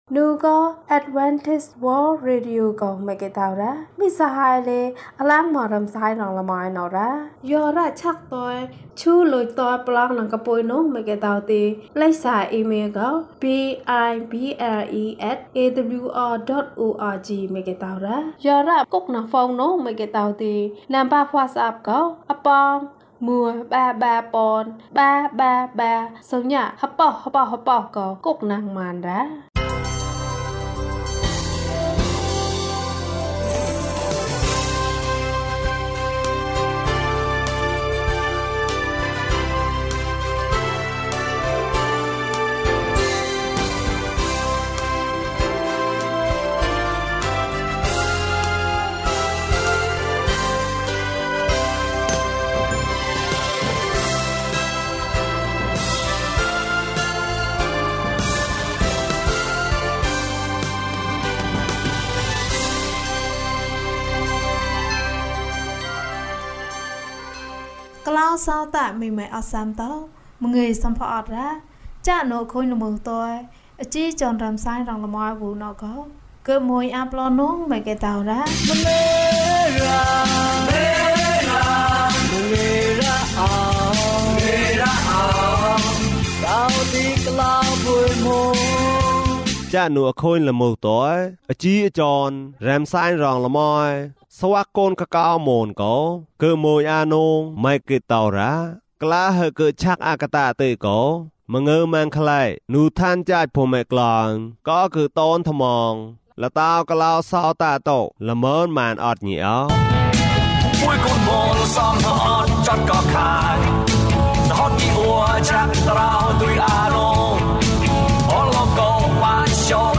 လုံခြုံရေးကောင်းသည်။၀၁ ကျန်းမာခြင်းအကြောင်းအရာ။ ဓမ္မသီချင်း။ တရားဒေသနာ။